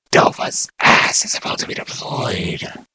mvm_bomb_alerts03.mp3